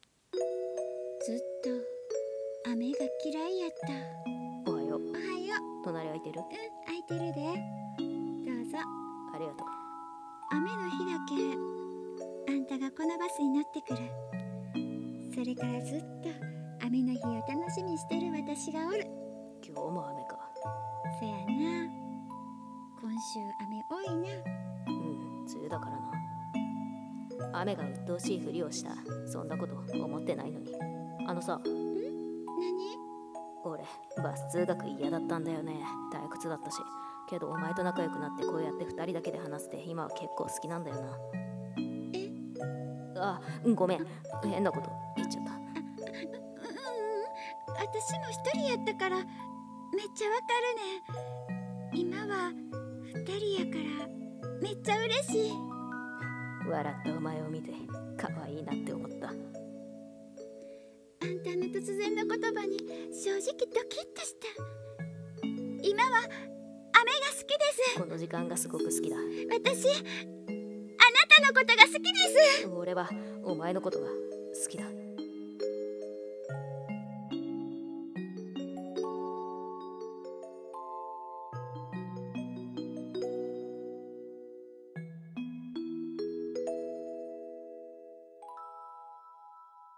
声劇【雨の朝】※コラボ用声劇